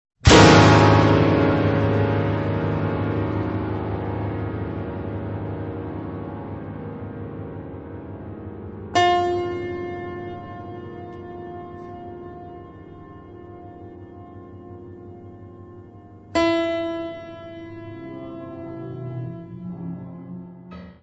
barrel organ
piano, prepared piano
tuba, serpent
double bass
percussion
Music Category/Genre:  New Musical Tendencies